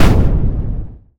boom.ogg